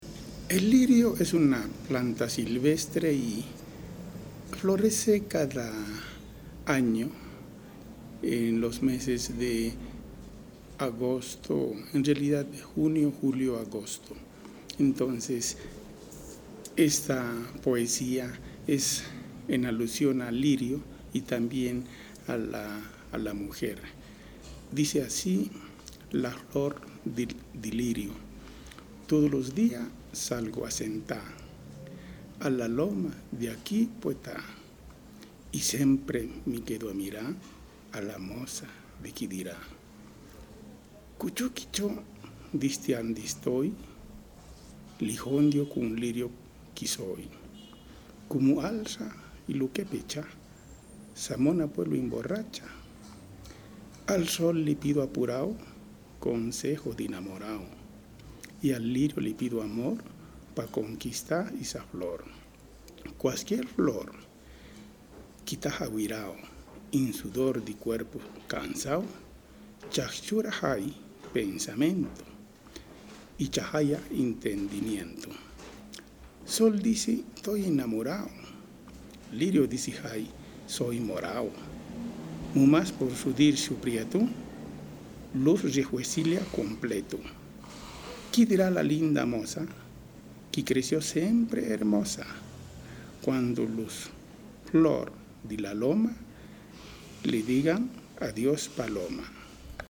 lee su primer poema